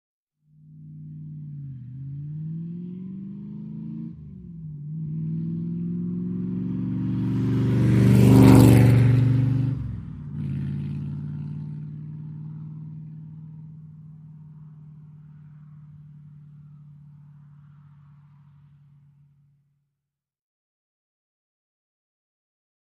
Cobra; By, Medium; Low Drone With Approach, Shifting Gears, Medium By, Shift Gears And Rev Down After Away. Medium Perspective. Sports Car, Auto.